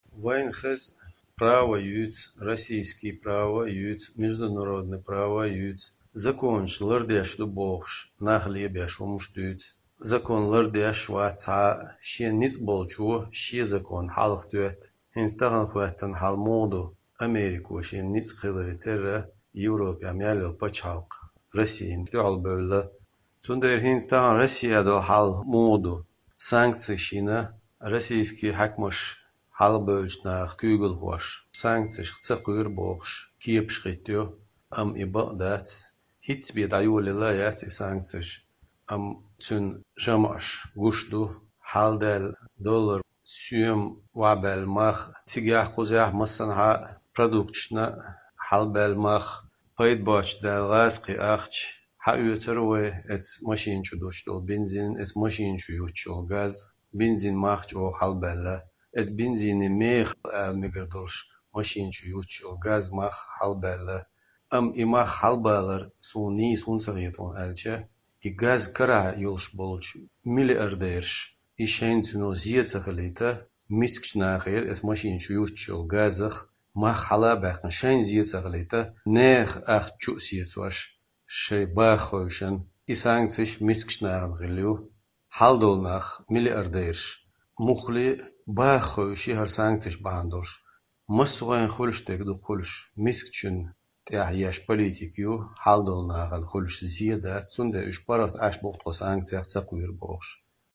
Масала, Нохчийчоьнан базаршкара, туьканашкара мехаш бовзийтира Маршо Радиона телефонехула маьI-маьIнерчу массех вахархочо.